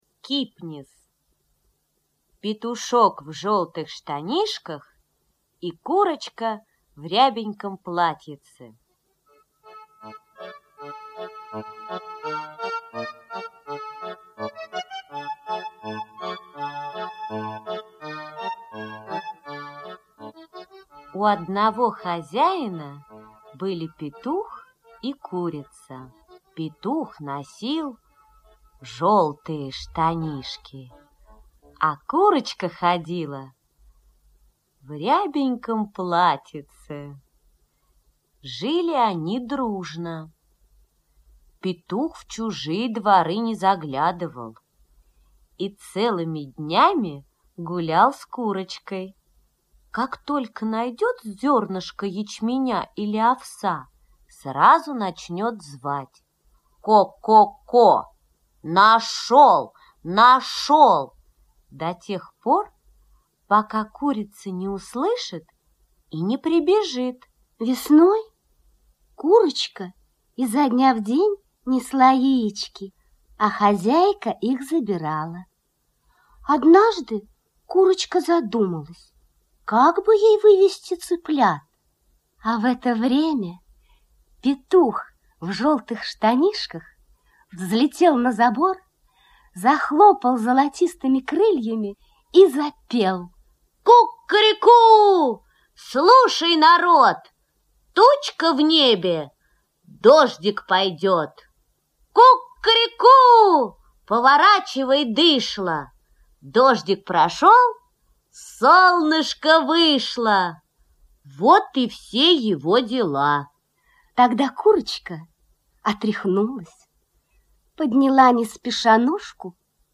Слушать онлайн сказку Петушок в желтых штанишках и курочка в рябеньком платьице - аудиосказка Кипниса И.Н.